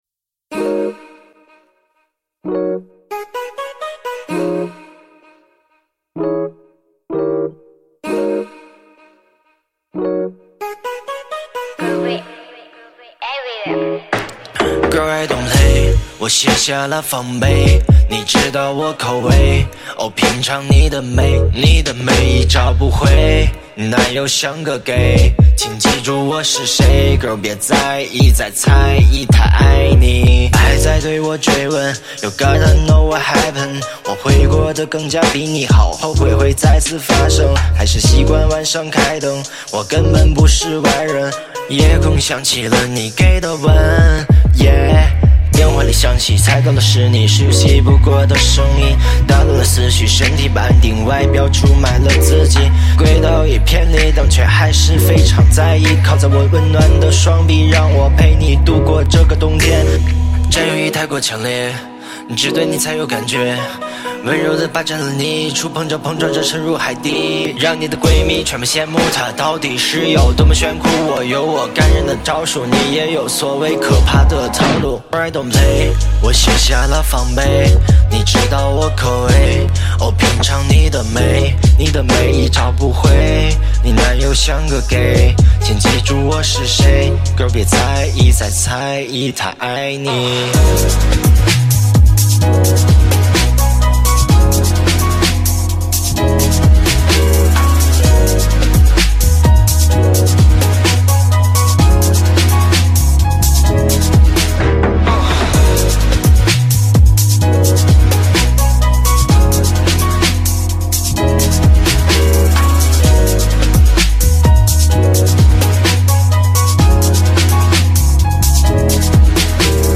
这是一首说唱歌曲，节奏和音准偏差严重，压拍错乱。调整整体音准相对准确加电之后 ，相对好很多了！